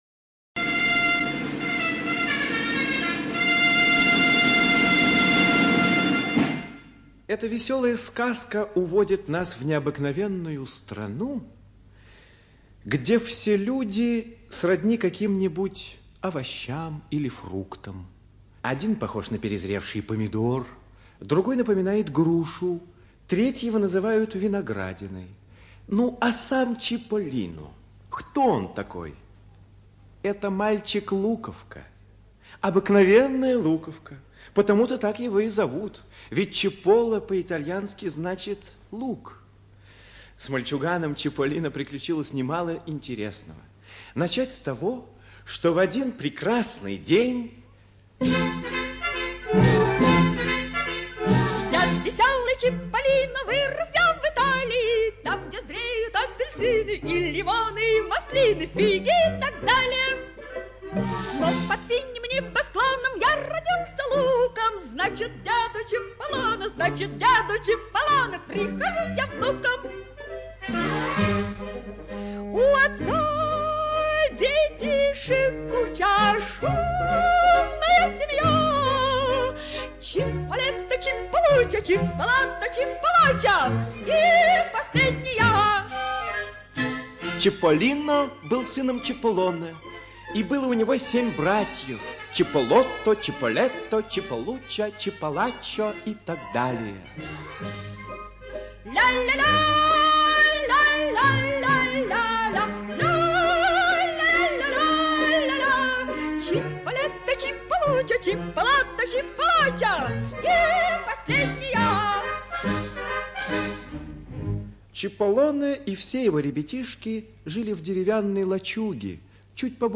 Приключения Чиполлино - аудиосказку Родари Д. Приключения мальчика-луковки Чиполлино, его братьев и жителей его городка.